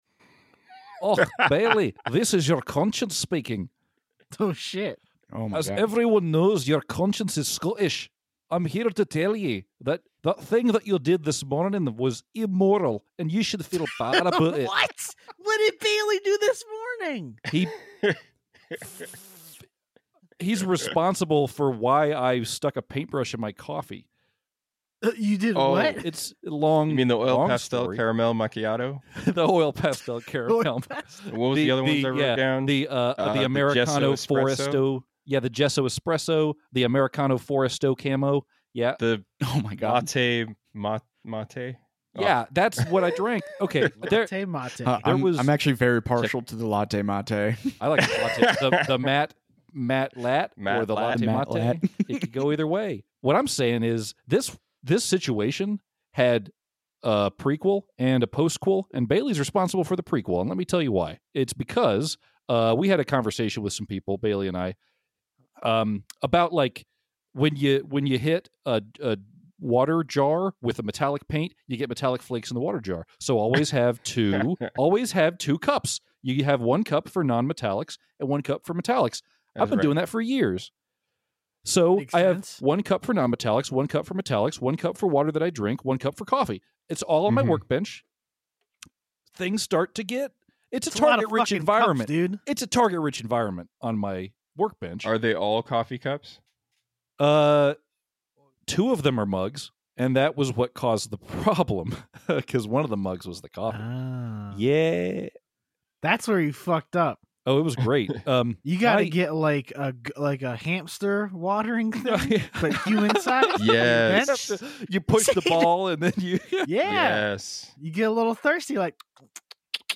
We sit down and talk about branching out and where the hobby cycle can lead you. In this kinda casual conversation, we talk about the one thing that brought us to all kinds of different roads.